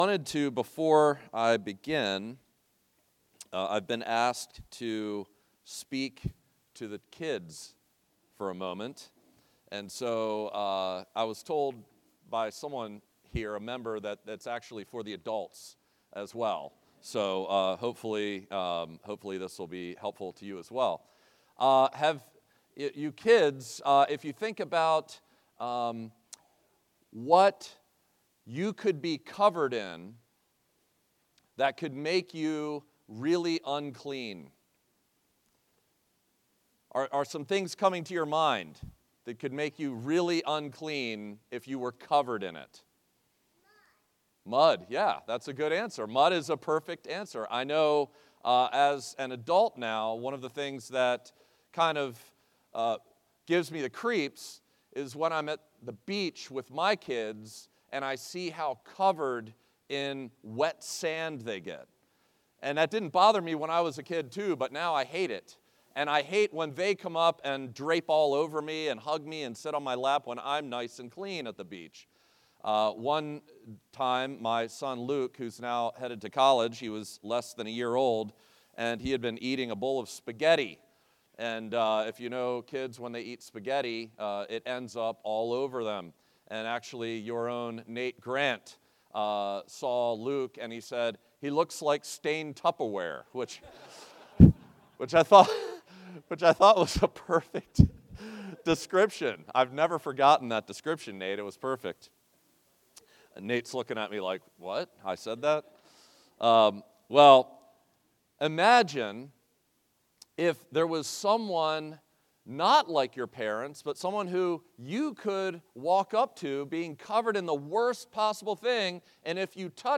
From Series: "Guest Sermons"